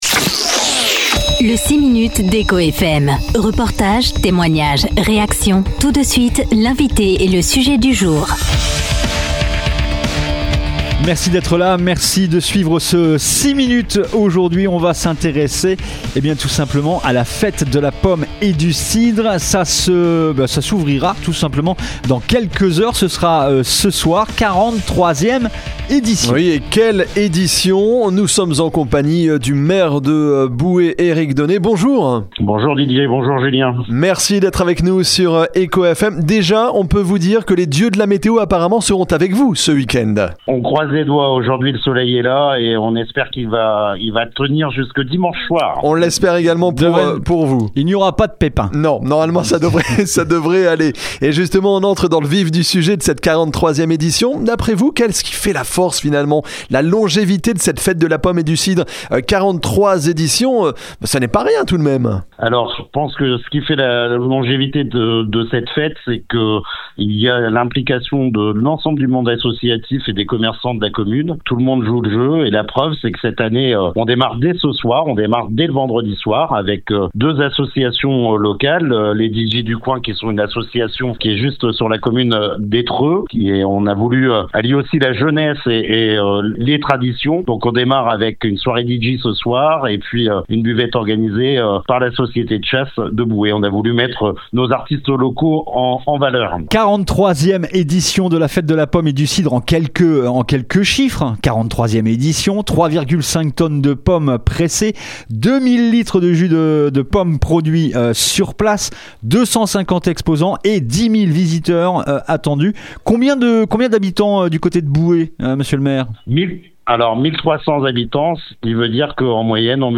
Les explications du maire de Boué Éric Donnay, invité du 6 minutes d'Echo FM ce vendredi 19 septembre.